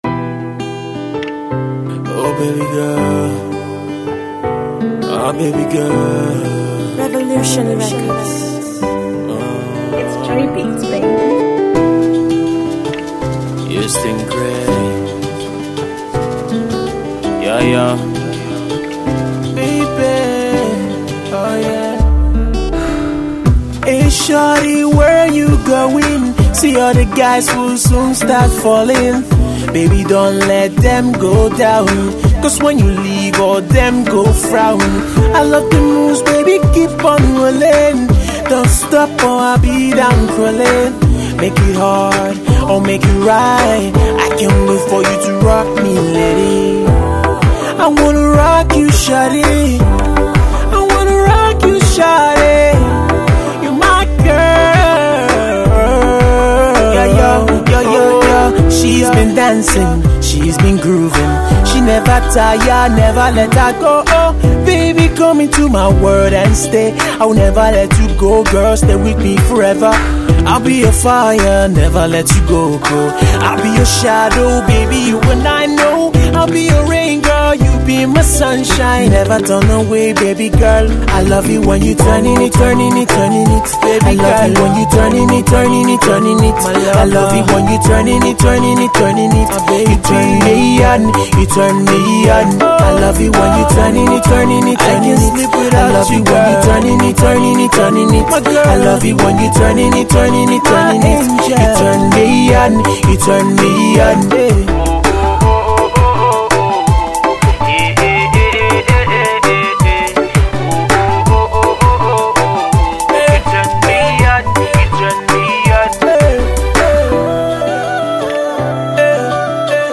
He debuts with these two Afro-R&B joints